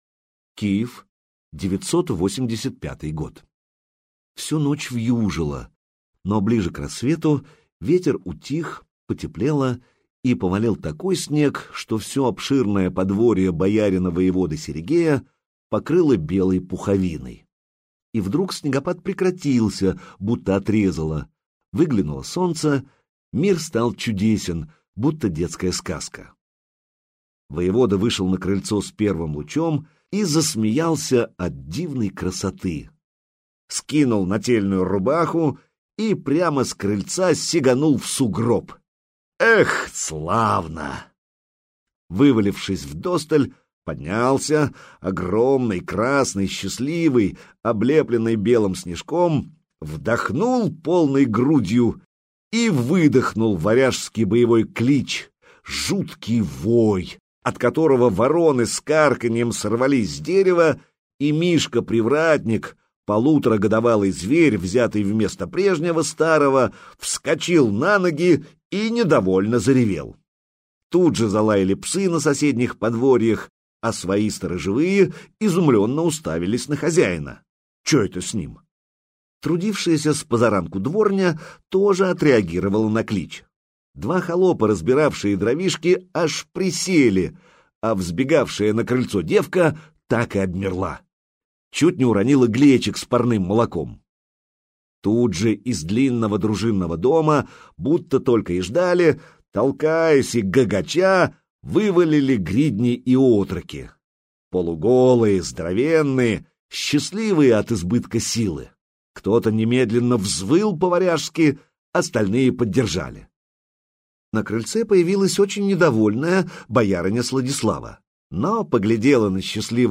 Аудиокнига Государь - купить, скачать и слушать онлайн | КнигоПоиск